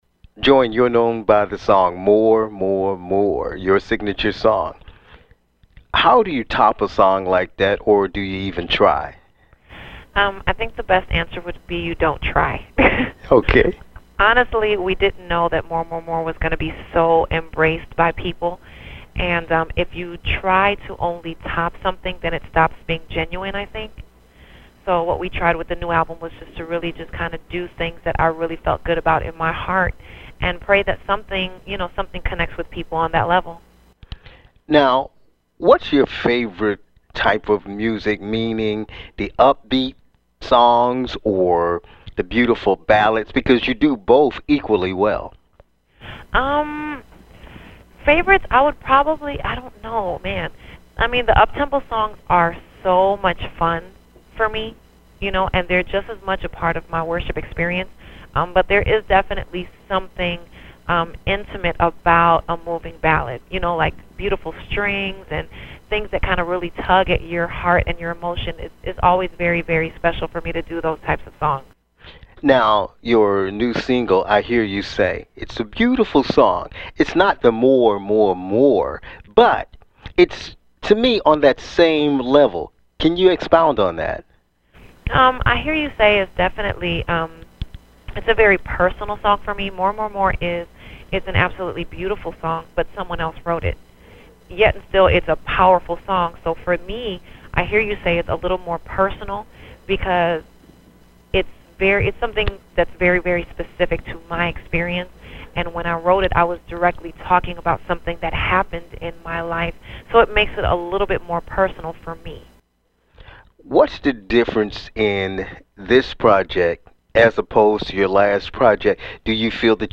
Star 94.1 Exclusive interview